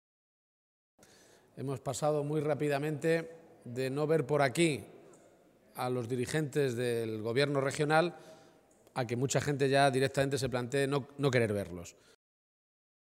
El secretario regional del PSOE de Castilla-La Mancha, Emiliano García-Page, ha participado esta mañana en el comité provincial del PSOE de Guadalajara en la localidad de Alovera.
Cortes de audio de la rueda de prensa